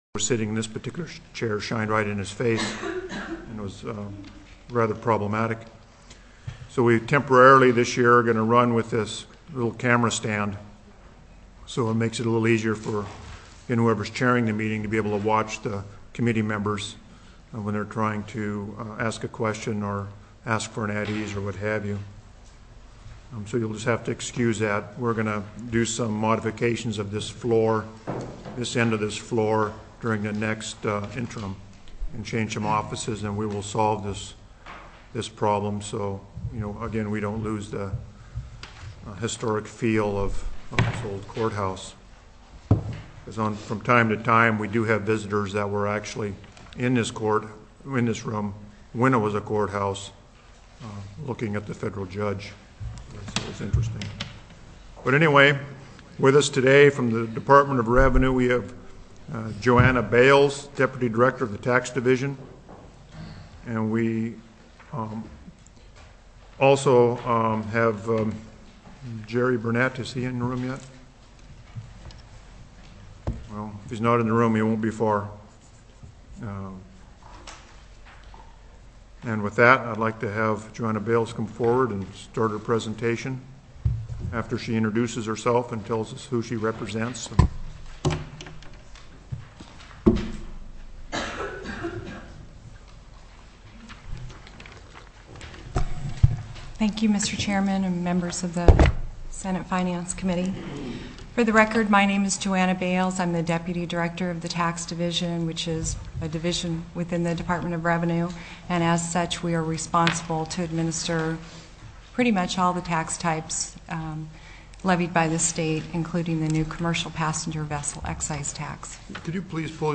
Co-Chair Stedman called the Senate Finance Committee meeting to order at 9:06:24 AM .